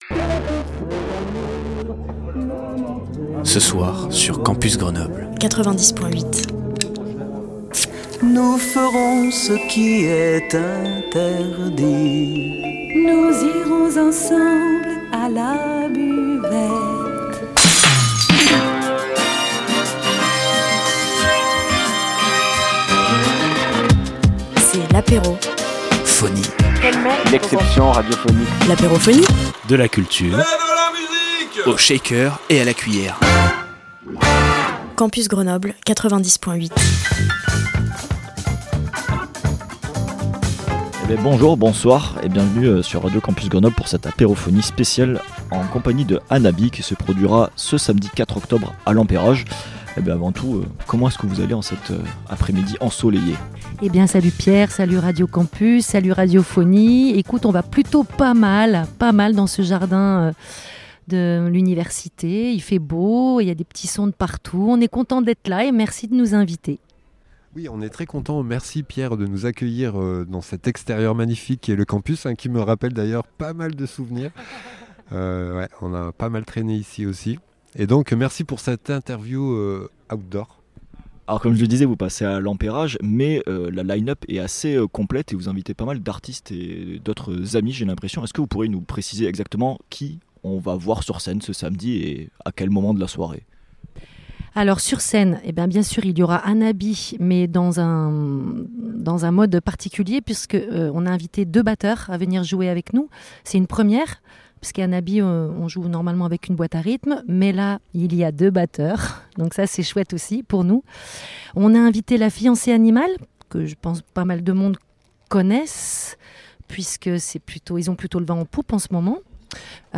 L’Apérophonie c’est votre apéro radiophonique quotidien à 18h !